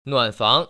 [nuăn//fáng] 누안팡